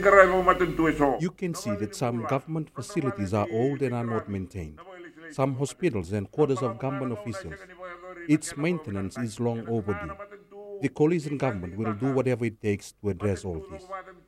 This has been highlighted by Prime Minister Sitiveni Rabuka while opening the Lau Provincial Council Meeting in Suva yesterday.